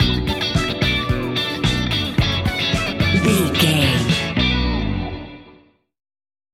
Ionian/Major
house
electro dance
synths
techno
trance
instrumentals